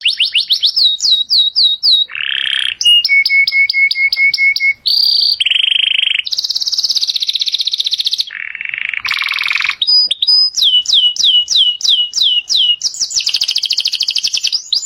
ringtone pajarito 9